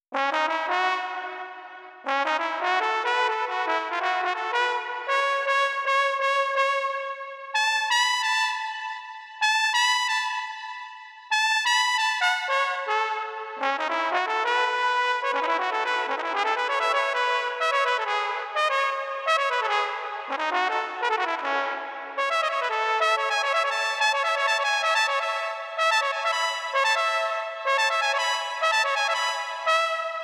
trombone_saxo_02_long.wav